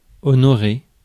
Ääntäminen
IPA: [ɔ.nɔ.ʁe]